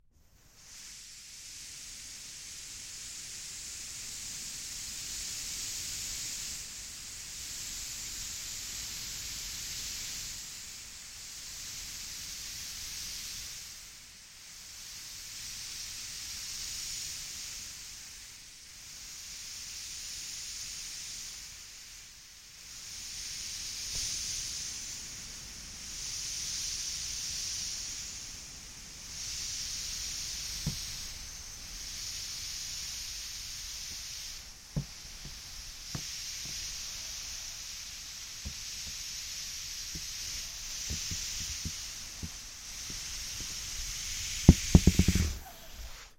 气球 " 气球放气长 1
描述：录制为通过操纵气球创建的声音集合的一部分。
Tag: 折边 放气 气球 放屁